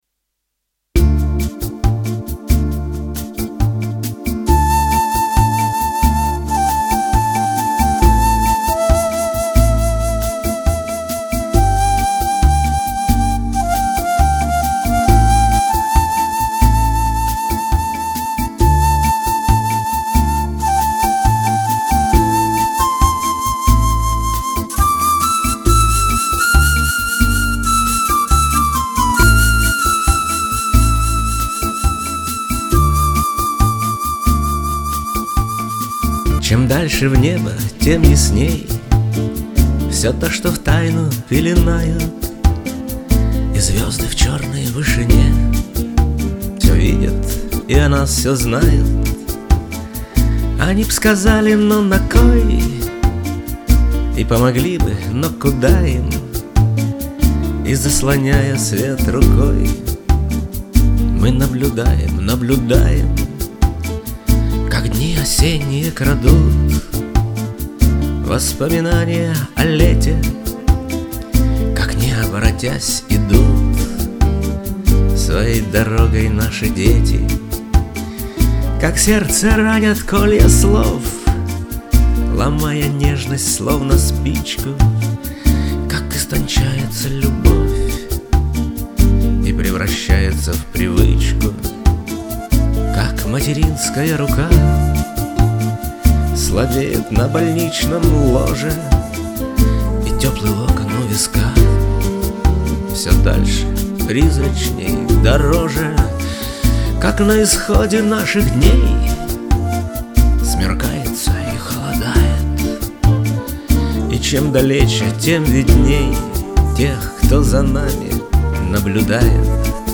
òàêæå âîîáùå òî, ÷òî òî ÷òî ýòî îäèíàêîâàÿ ôèãíÿ, êðîìå êîíå÷íî ëàìáàäû èç ñèíòåçàòîðà â ïîäëîæêå, ýòî âîîáùå çà ãðàíüþ